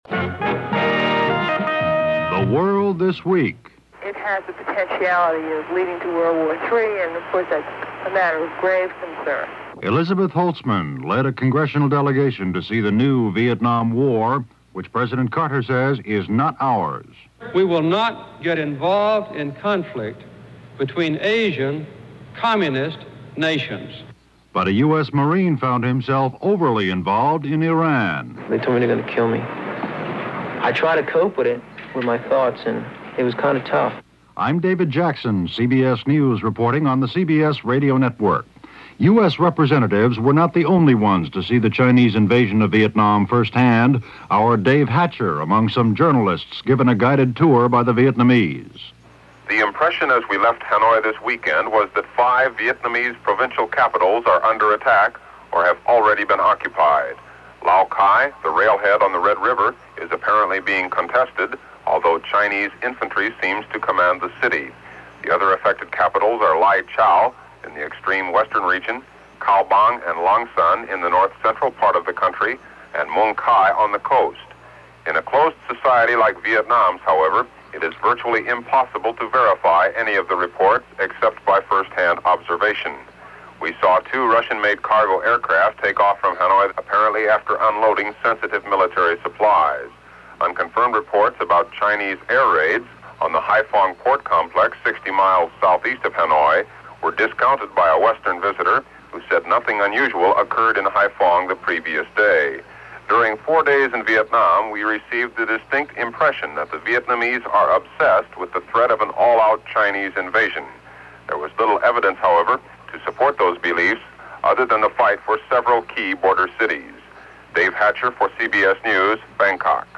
February 25, 1979 - The Sino-Vietnam War: For a change, not in the frying pan. But Iran . . . - news for this week in 1979.